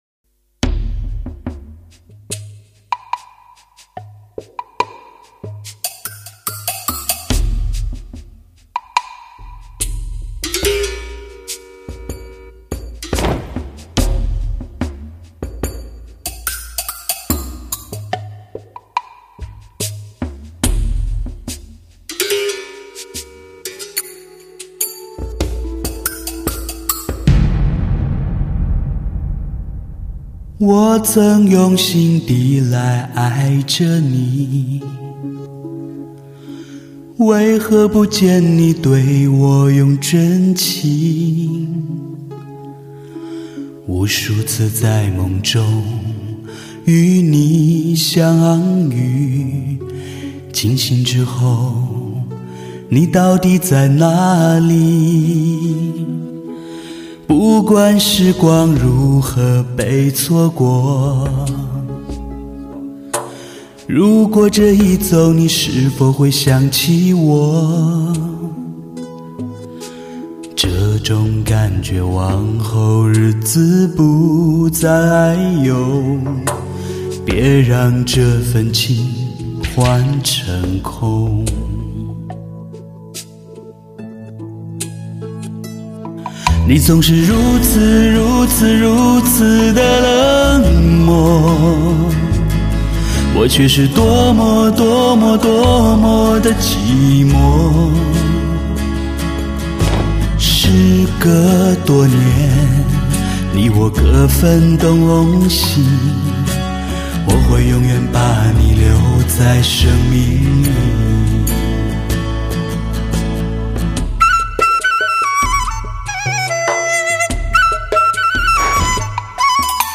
，全方位环绕，高临场感
类型: 汽车音乐